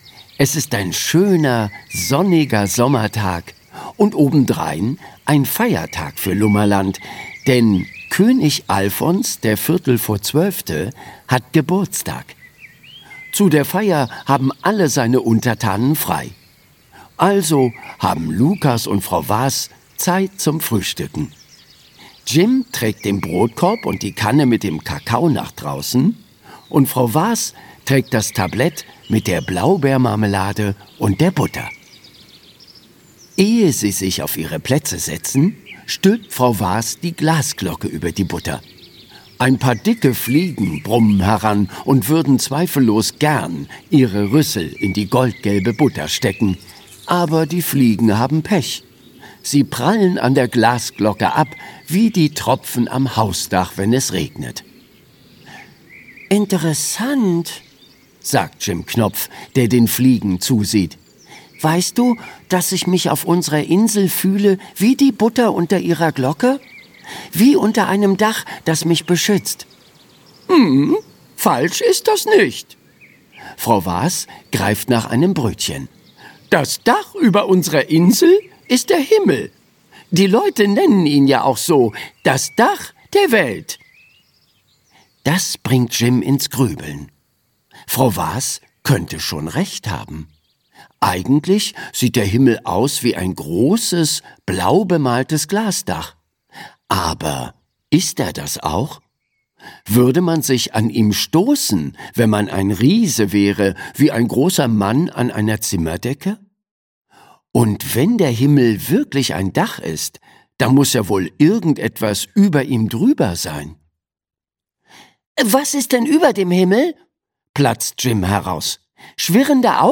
Jim Knopf findet's raus - Michael Ende - Hörbuch